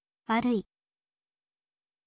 warui, วะรึอิ